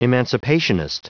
Prononciation du mot emancipationist en anglais (fichier audio)
Prononciation du mot : emancipationist